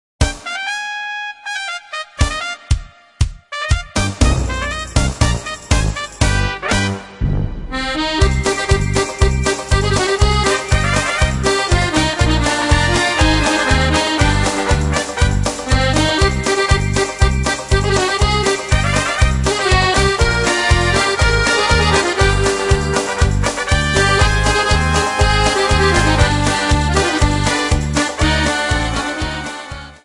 Accordion Music 3 CD Set.